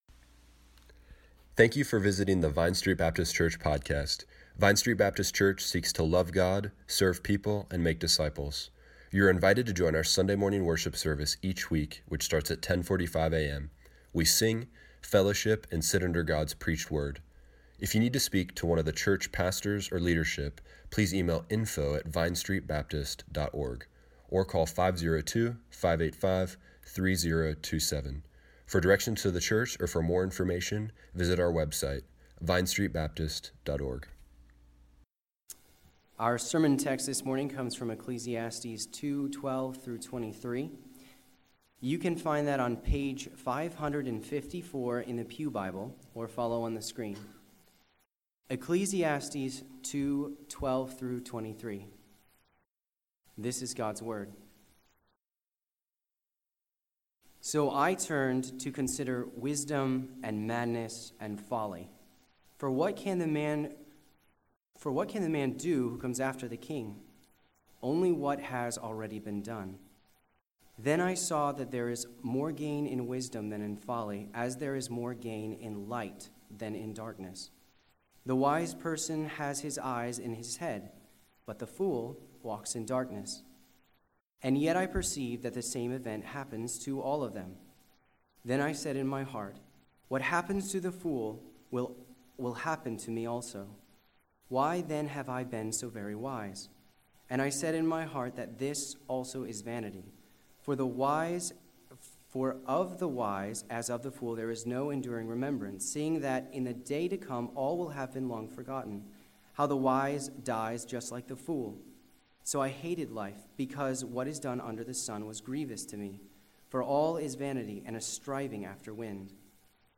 Service Morning Worship